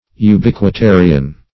Search Result for " ubiquitarian" : The Collaborative International Dictionary of English v.0.48: Ubiquist \U"bi*quist\, Ubiquitarian \U*biq`ui*ta"ri*an\, n. [L. ubique everywhere: cf. F. ubiquiste, ubiquitaire.
ubiquitarian.mp3